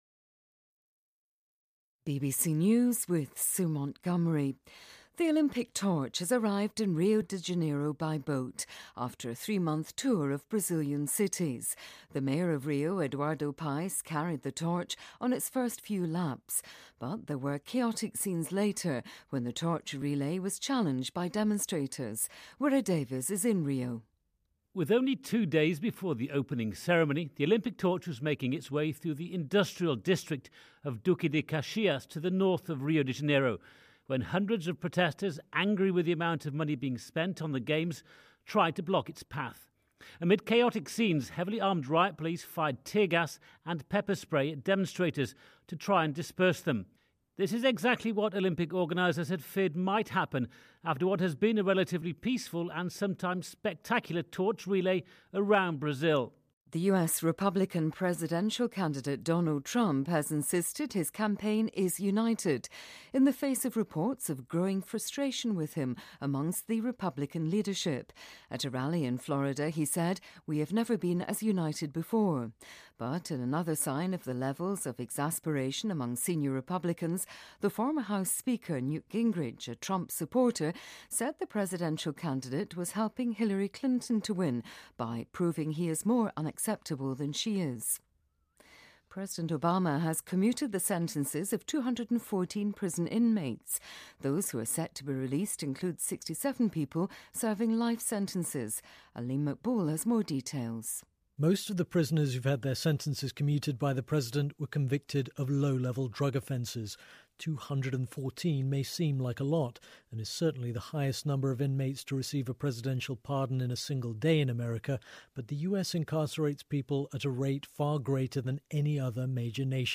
日期:2016-08-06来源:BBC新闻听力 编辑:给力英语BBC频道